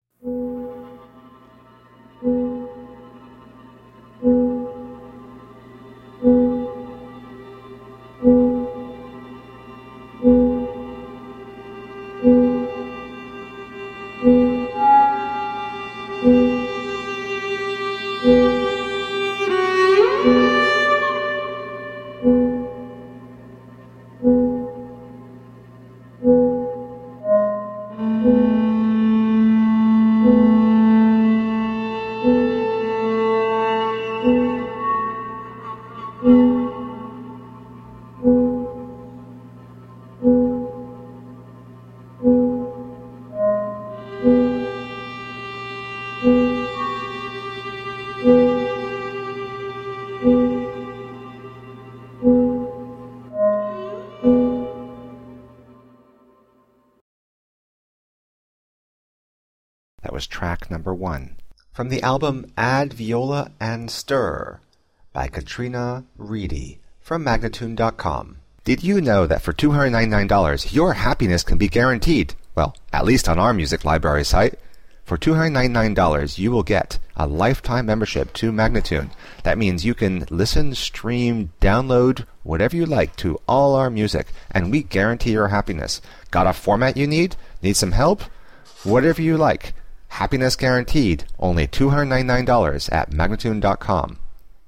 From fun and jazzy to moody and melancholic.